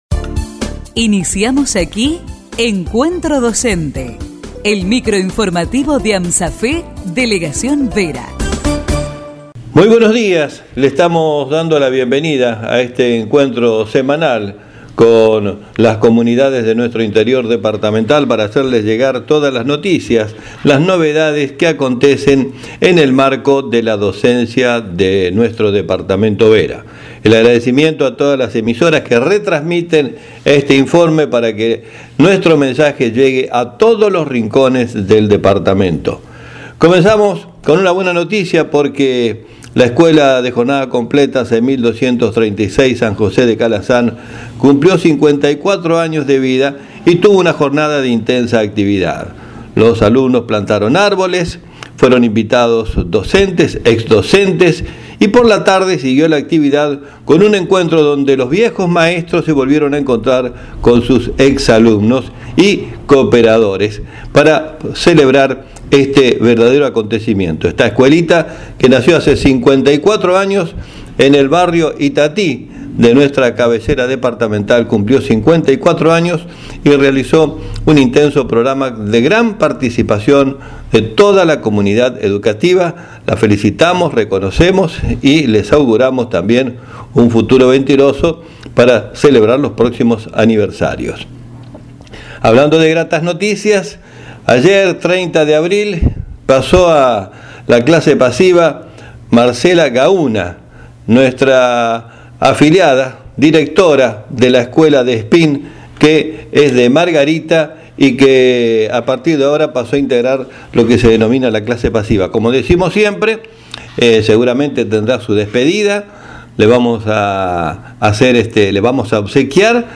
Micro informativo de Amsafe Vera.